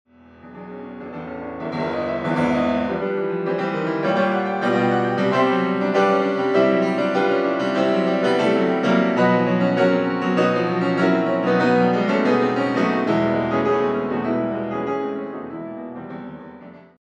Allegro molto